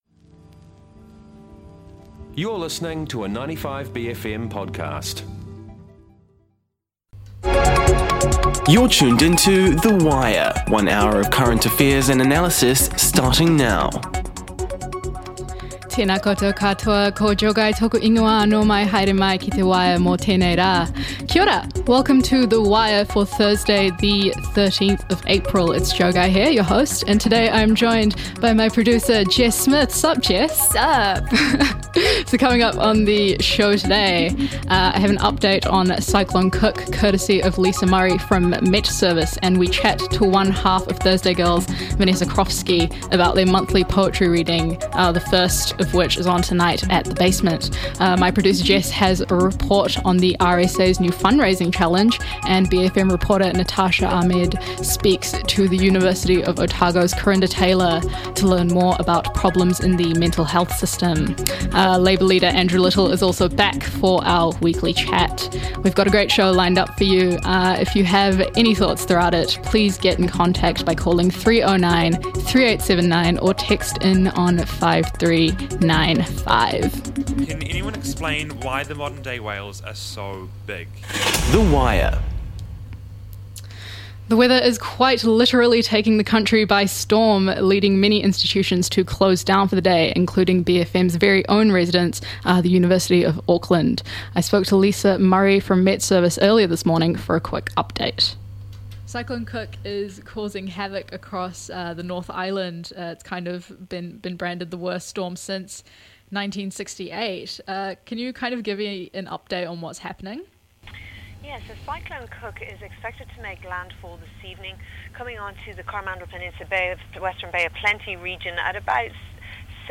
Andrew Little is also back for our weekly chat, this time live from a visit to Edgecumbe.